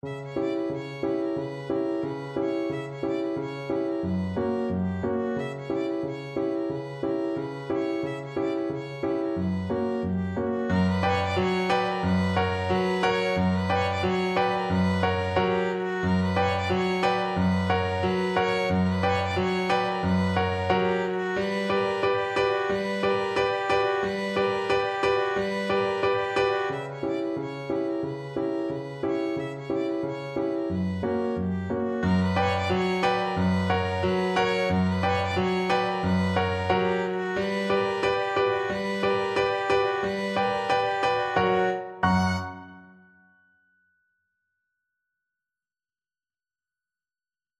Violin
Presto = 180 (View more music marked Presto)
B minor (Sounding Pitch) (View more B minor Music for Violin )
2/4 (View more 2/4 Music)
Traditional (View more Traditional Violin Music)